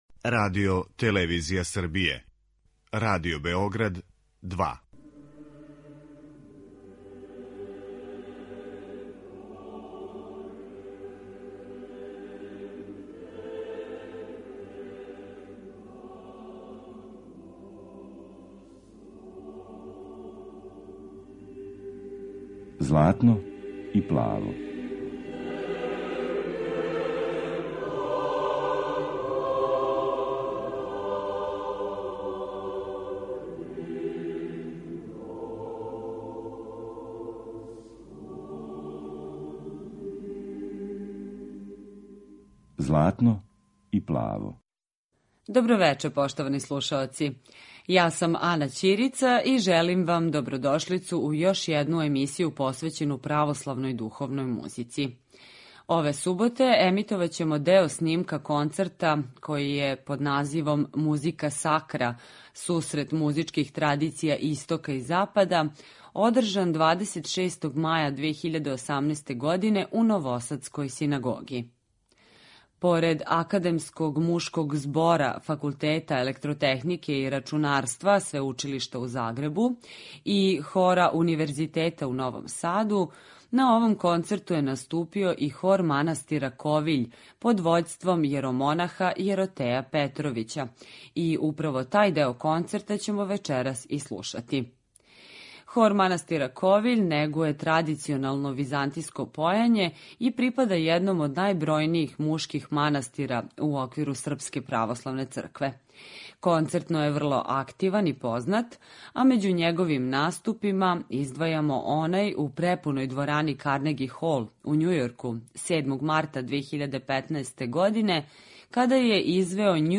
Емисија посвећена православној духовној музици.
Емитујемо снимак са концерта који је 26. маја 2018. године одржан у новосадској Синагоги. Поред Академског мушког збора Факултета електротехнике и рачунарства Свеучилишта у Загребу и Хора Универзитета у Новом Саду, тада је наступио и Хор манастира Ковиљ под вођством Јеротеја Петровића, данас викарног епископа топличког.